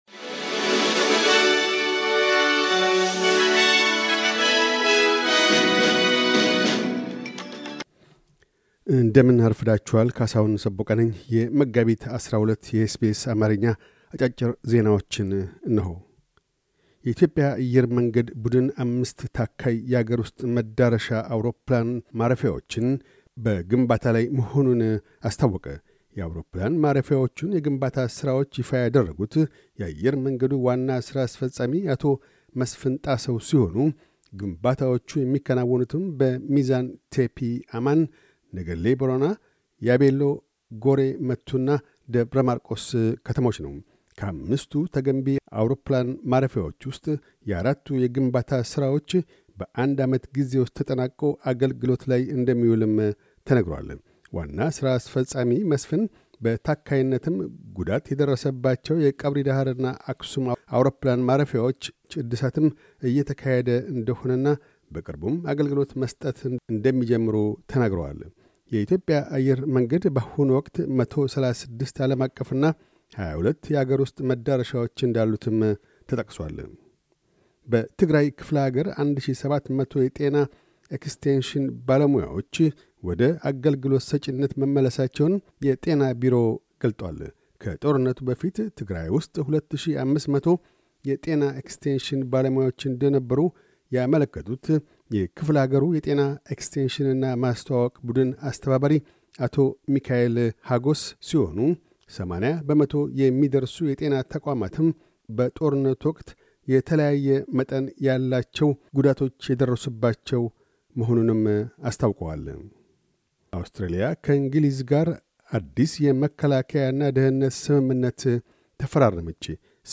ታካይ ዜናዎች